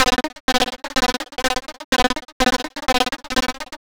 tx_perc_125_mania.wav